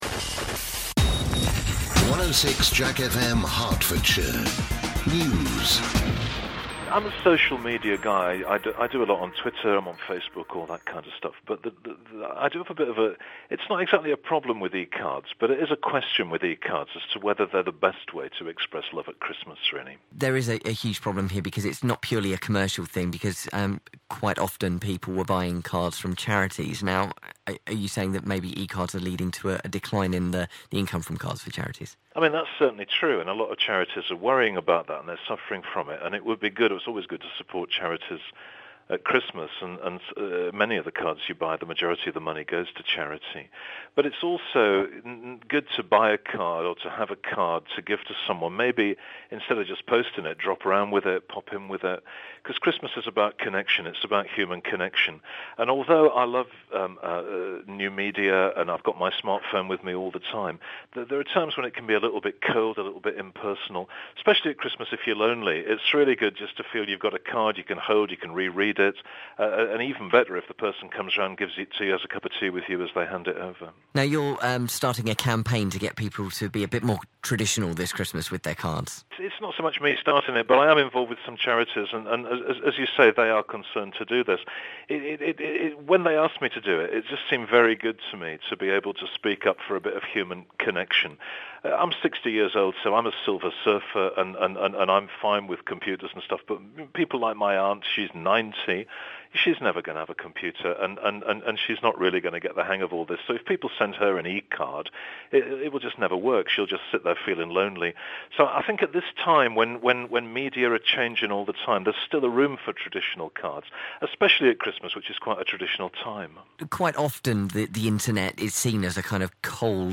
The Bishop of Hertford is joining calls for people to ditch e-cards this year, and send the real thing instead. Rt Revd Paul Bayes speaks to JACK fm...